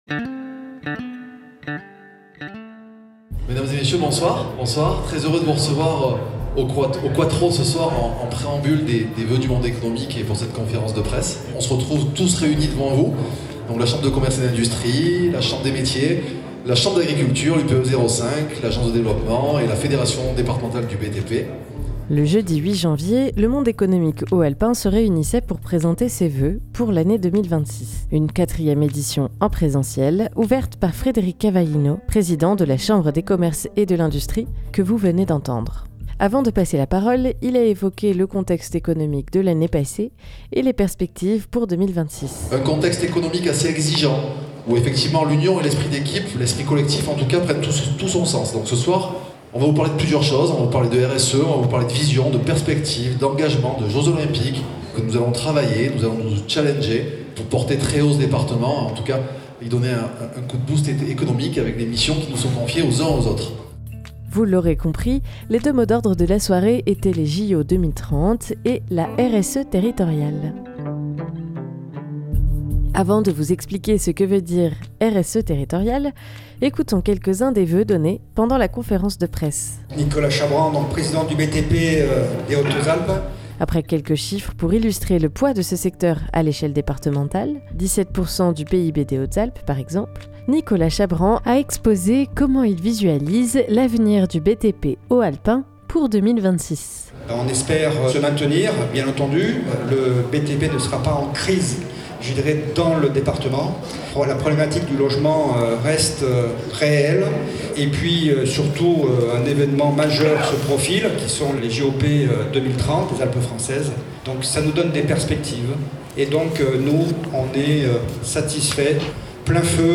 Retour sur les voeux et les témoignages de quelques signataires de cette charte RSE Territoriale. 260108 - Voeux CCI - Edit v5.mp3 (61.8 Mo)